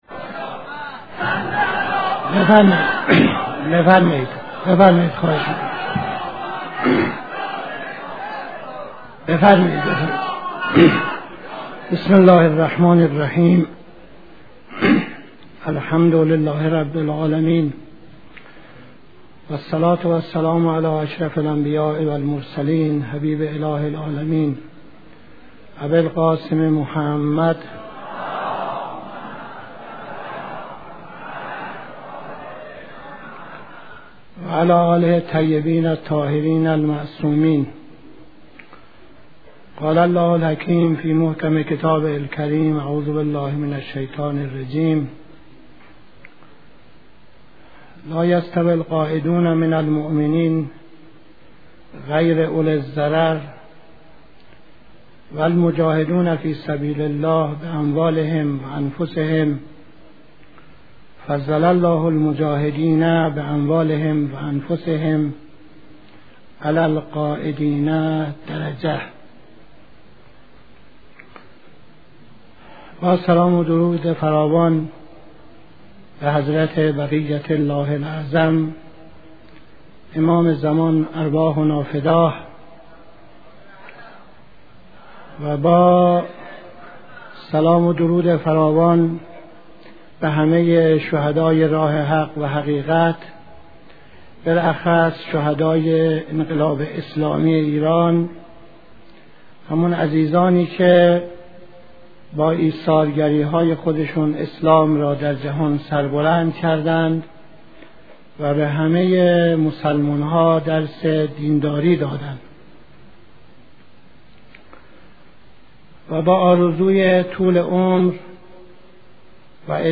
قبل از خطبه‌های نماز جمعه تهران 11-10-66